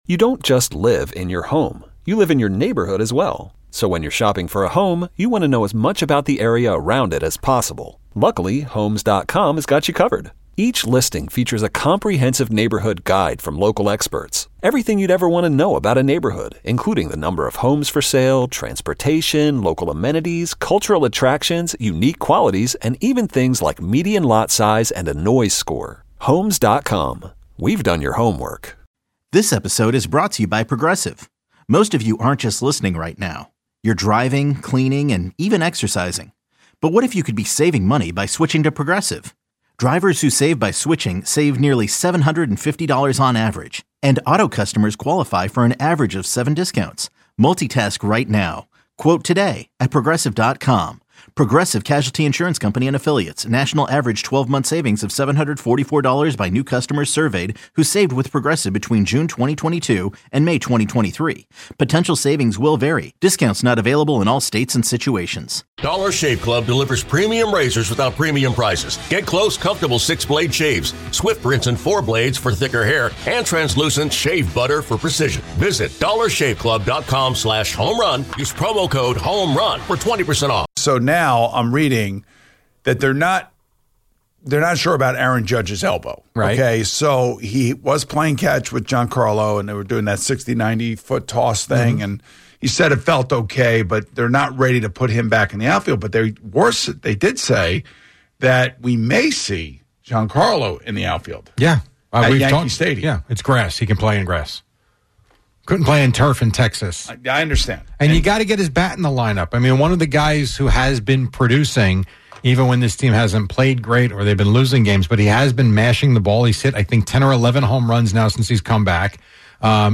We talked about the need for the Yankees to get Giancarlo Stanton’s bat back in the lineup. A caller who just retired said he’s not sure what to do with his time.